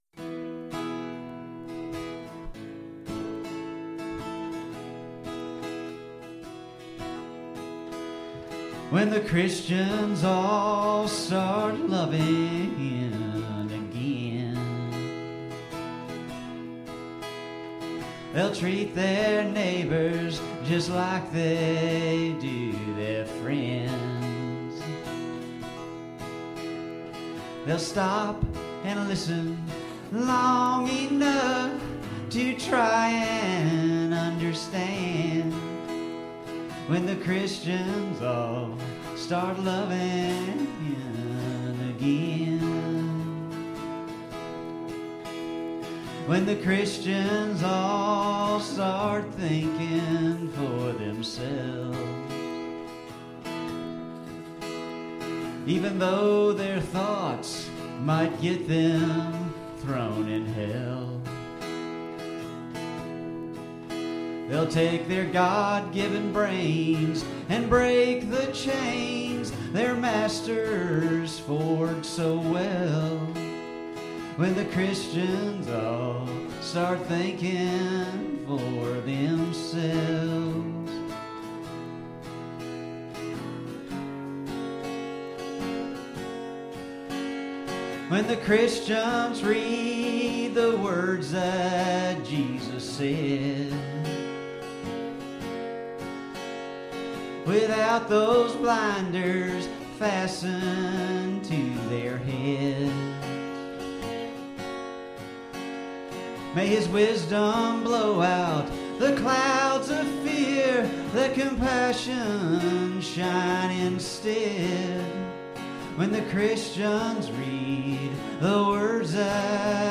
Capo 2; Play D